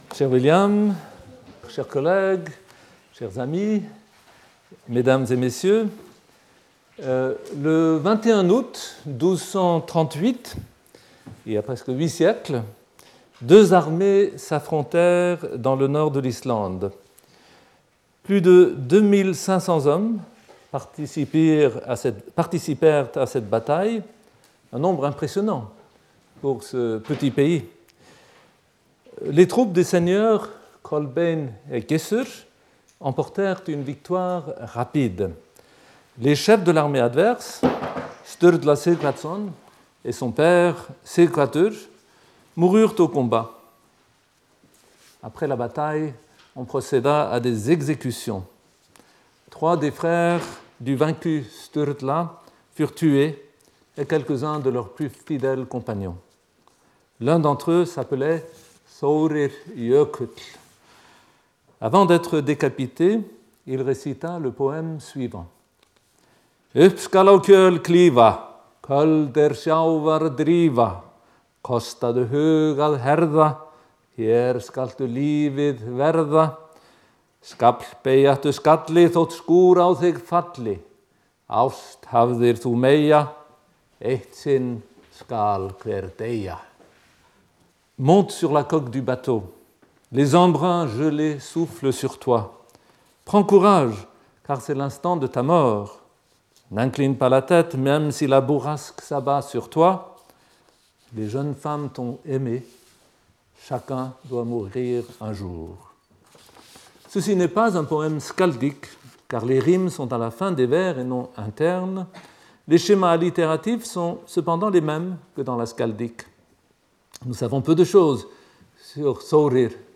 Conférencier invité